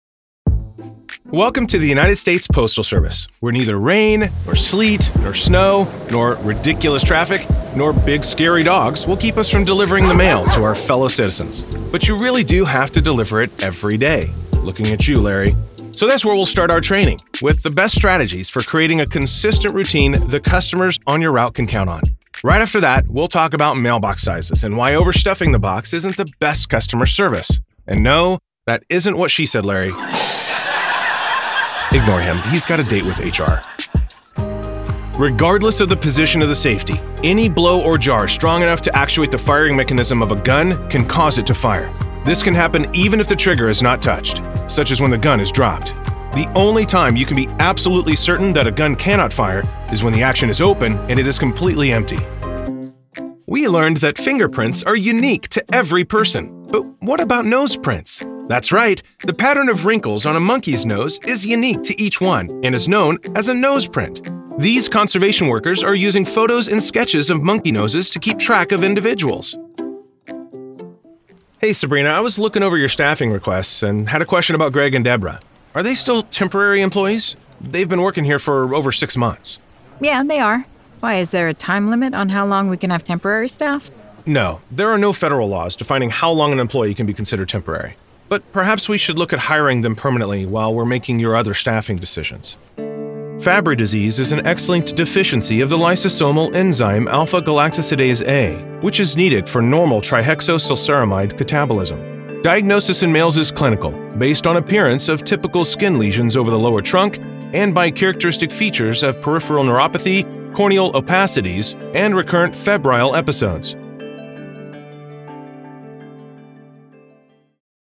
Male
English (North American)
Yng Adult (18-29), Adult (30-50)
My voice has been described as warm, genuine, authentic, trustworthy, authoritative, knowledgeable, inviting, engaging, encouraging, high-energy, believable, down-to-earth, informative, sincere, big, booming, and relatable.
E-Learning
Words that describe my voice are warm, genuine, trustworthy.